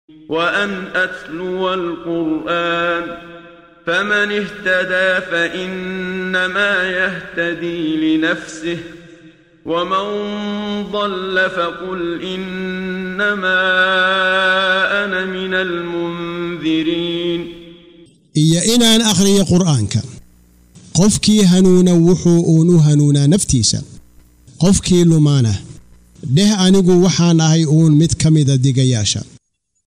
Waa Akhrin Codeed Af Soomaali ah ee Macaanida Suuradda An-Namal ( Quraanjada ) oo u kala Qaybsan Aayado ahaan ayna la Socoto Akhrinta Qaariga Sheekh Muxammad Siddiiq Al-Manshaawi.